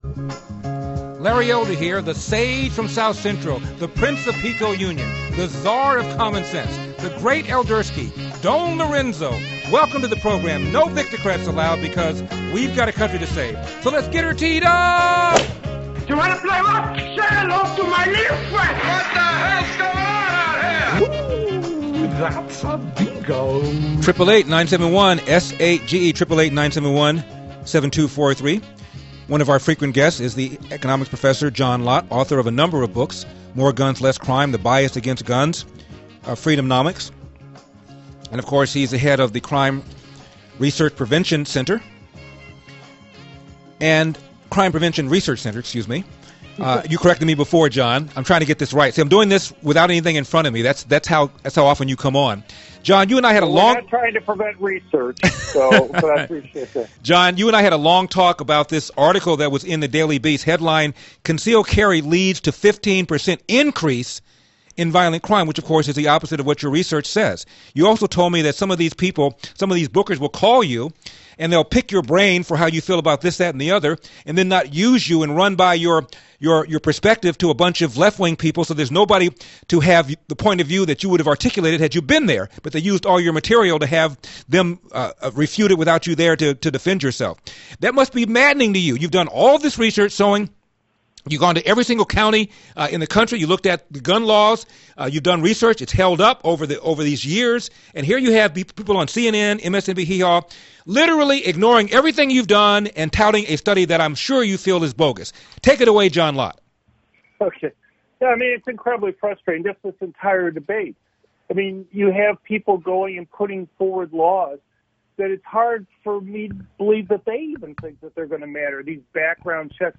Dr. John Lott joined Larry Elder on his national radio show to discuss the current debate on guns.